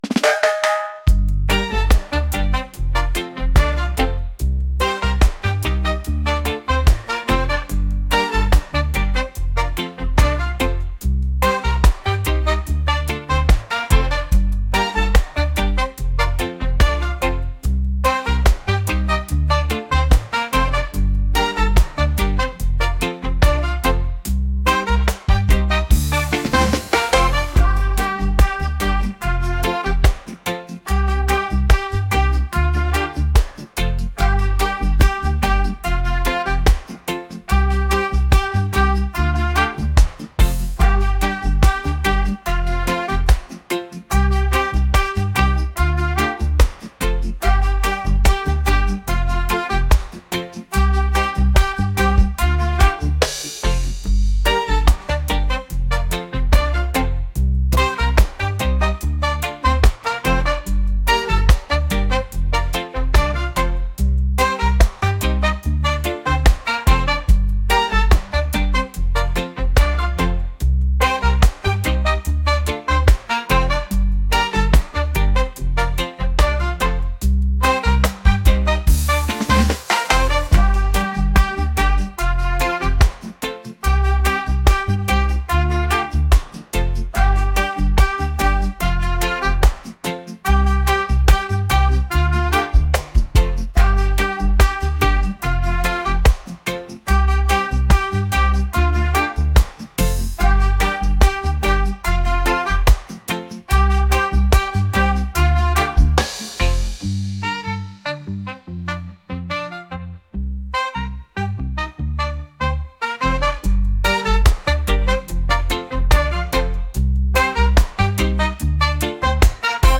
reggae | funk | soul & rnb